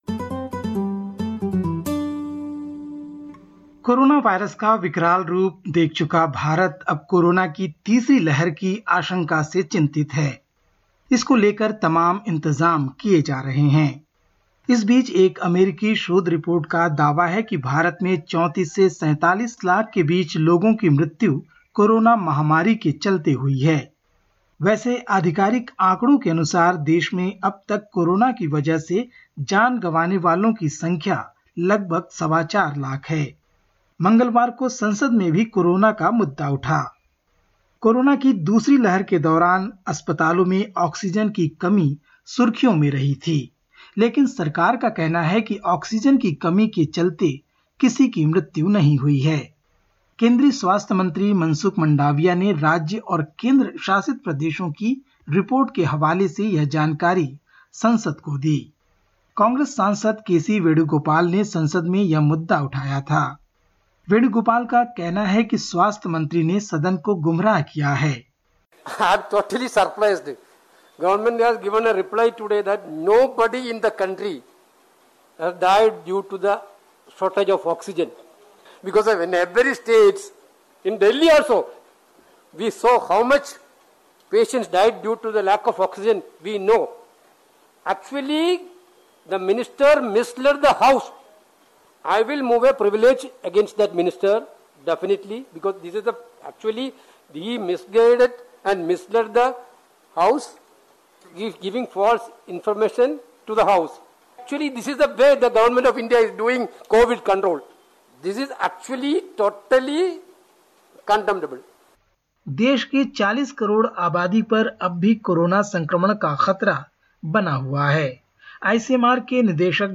भारत के समाचार